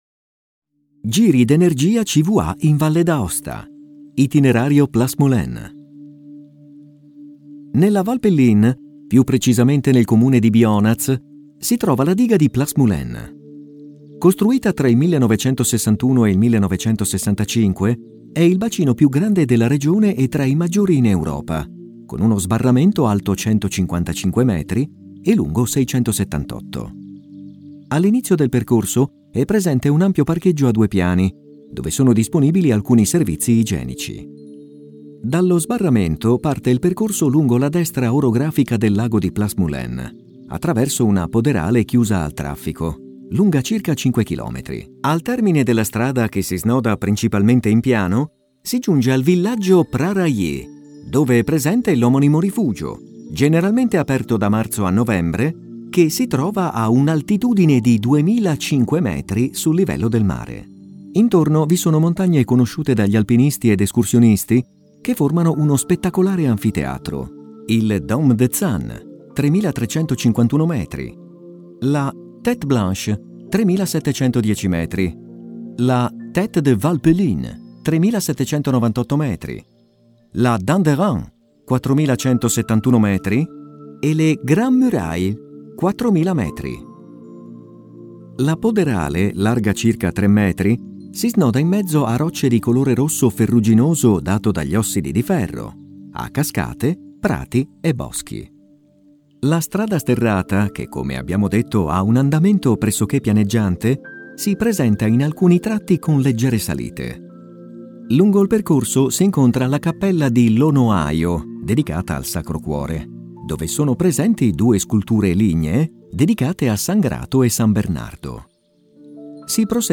Audioguida Video LIS